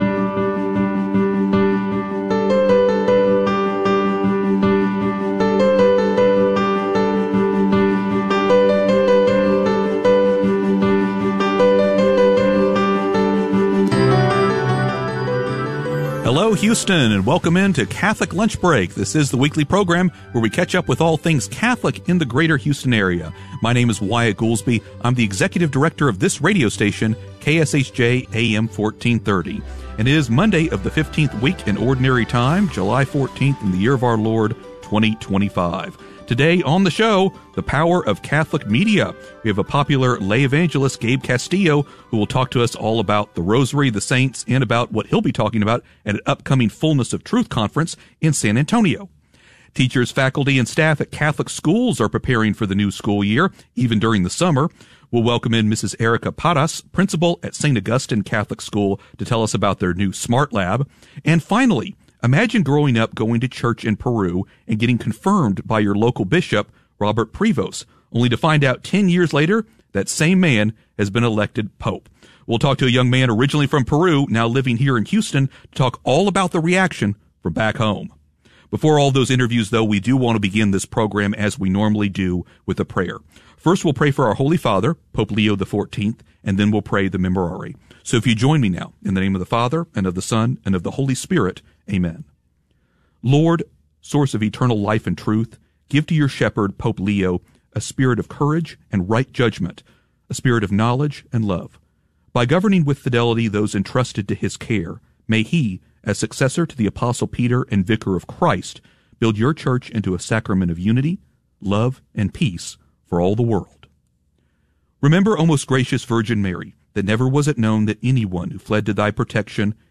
It airs live every Monday at noon in Houston on AM 1430 KSHJ, and podcasts here for your listening convenience. We’re going to talk to priests from every parish in the archdiocese, Catholics doing amazing things that you haven’t heard of, and you might even learn something about your faith without even trying.